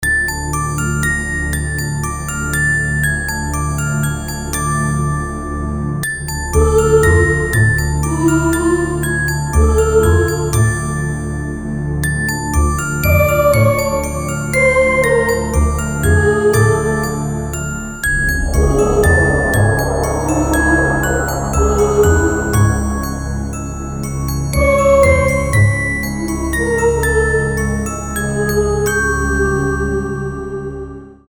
без слов
инструментальные
пугающие
ксилофон
мистические
Музыкальная шкатулка
жуткие